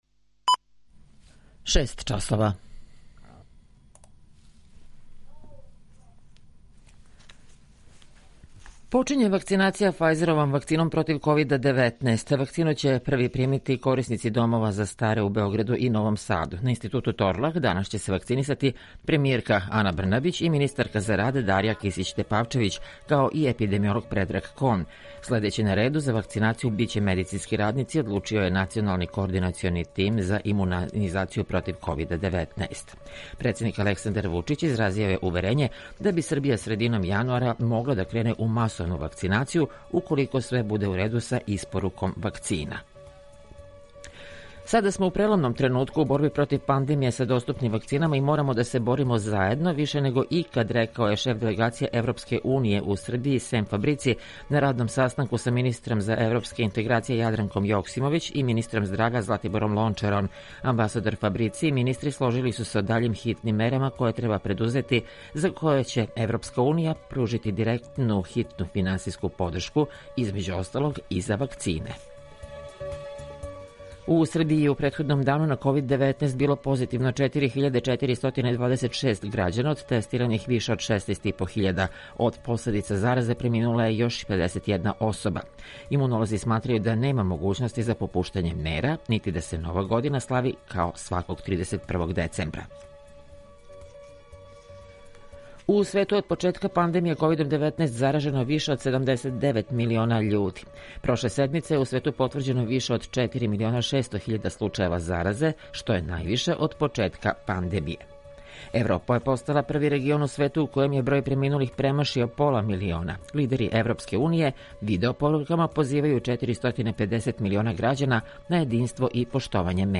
Божић у условима пандемије - говори Станислав Хочевар, београдски надбискуп и митрополит
Јутарњи програм из три студија
У два сата, ту је и добра музика, другачија у односу на остале радио-станице.